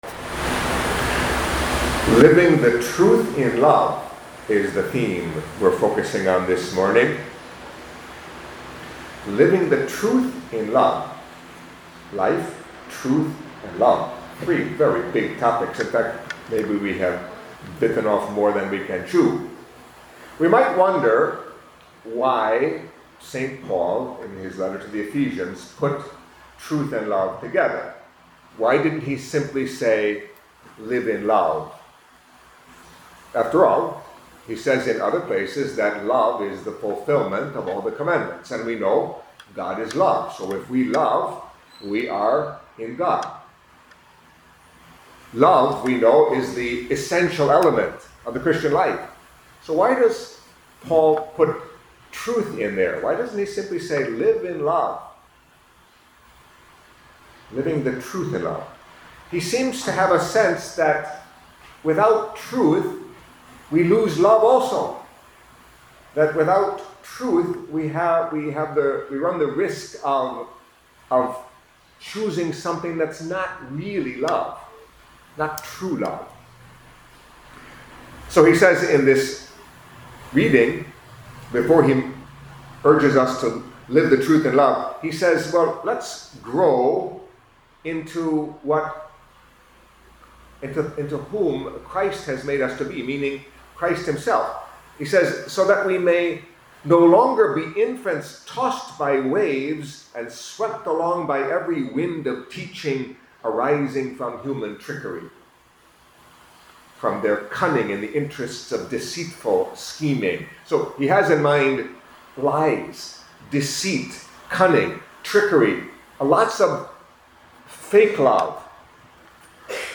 Catholic Mass homily for Saturday of the Twenty-Ninth Week in Ordinary Time